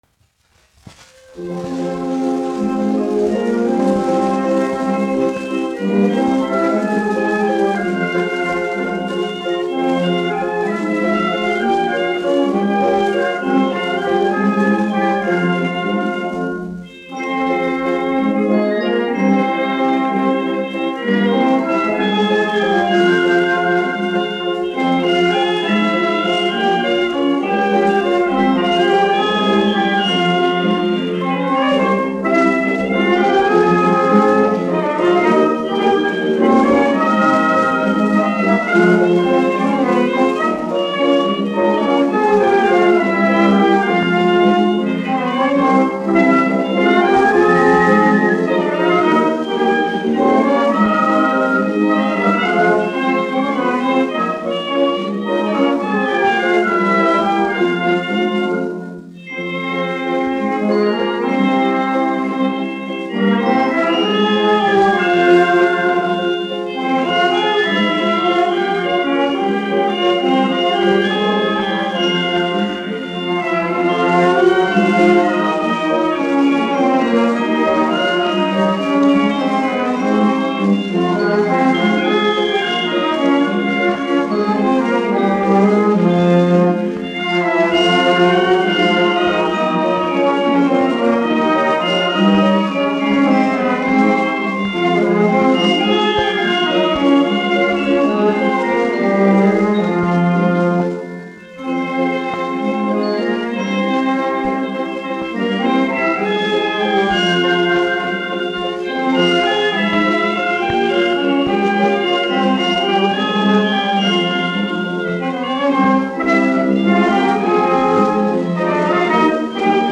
Marcella (mūzikas grupa), izpildītājs
1 skpl. : analogs, 78 apgr/min, mono ; 25 cm
Valši
Latvijas vēsturiskie šellaka skaņuplašu ieraksti (Kolekcija)